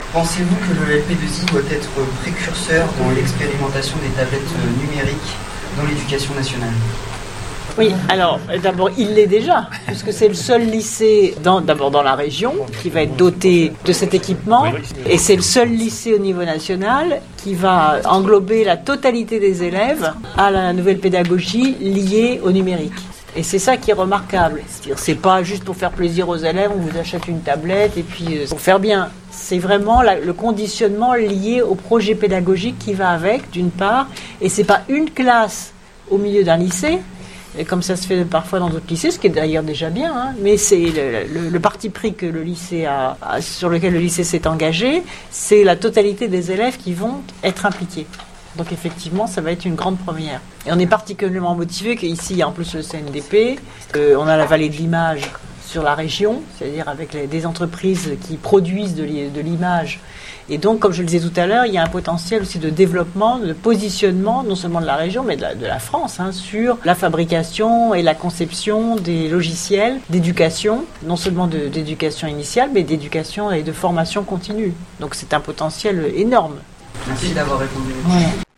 Lors de la célébration des 25 ans d’informatique dans l’enseignement : "Du minitel aux tablettes", le 23 novembre 2012 dernier , l’équipe d’On Air ACF a interviewé Mme la Présidente de Région Ségolène Royal :